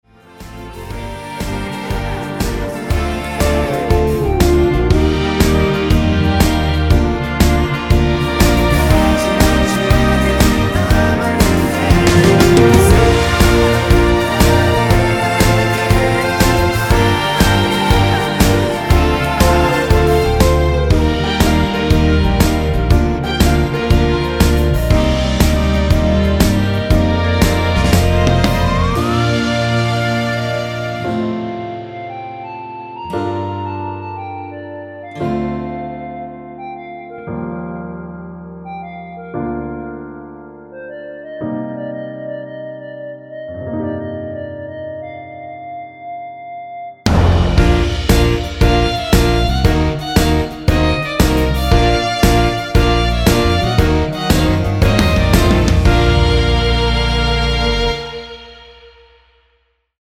엔딩이 길고 페이드 아웃이라서 노래끝나고 4마디 진행후 엔딩을 만들었습니다.(미리듣기 확인)
원키 멜로디와 코러스 포함된 MR입니다.
Bb
앞부분30초, 뒷부분30초씩 편집해서 올려 드리고 있습니다.